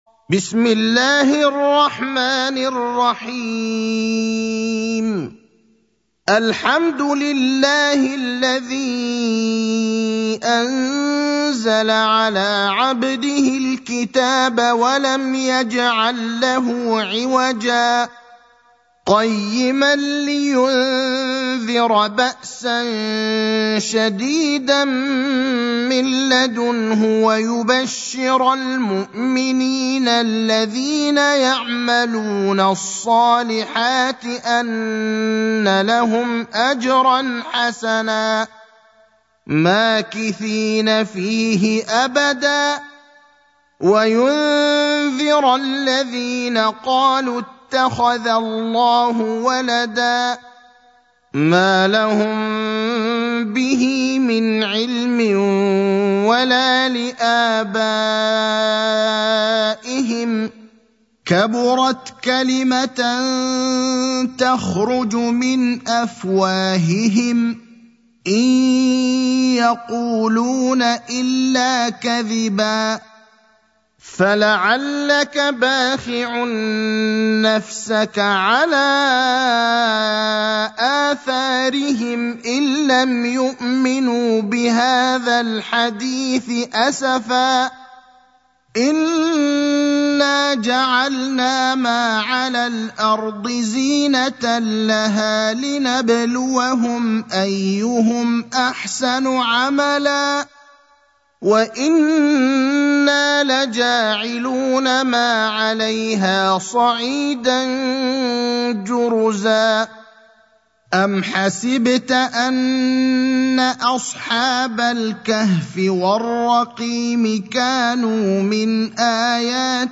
المكان: المسجد النبوي الشيخ: فضيلة الشيخ إبراهيم الأخضر فضيلة الشيخ إبراهيم الأخضر الكهف (18)